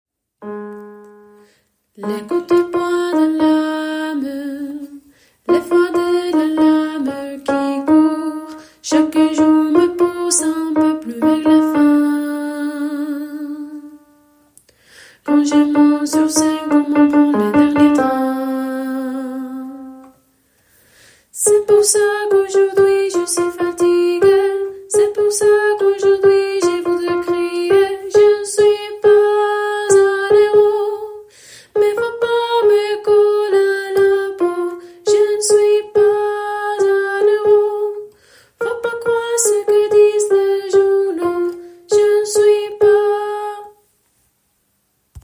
Soprane